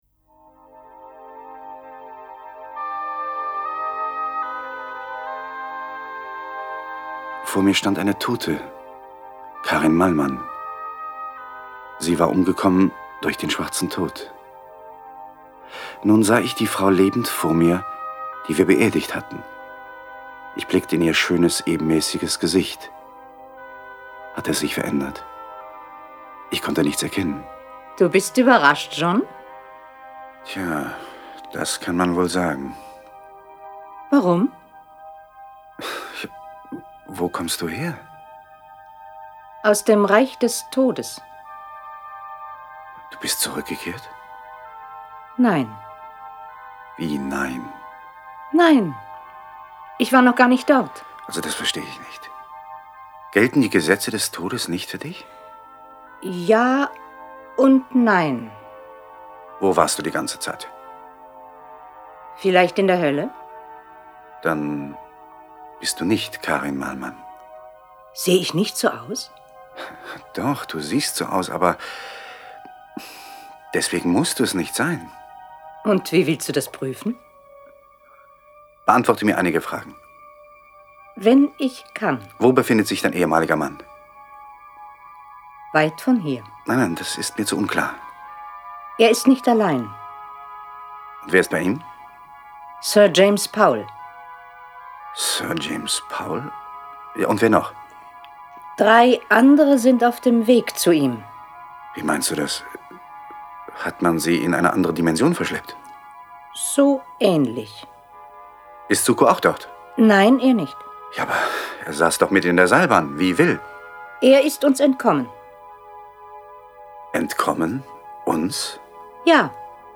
Jason Dark (Autor) diverse (Sprecher) Audio-CD 2016 | 1.